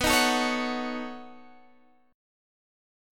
B9 Chord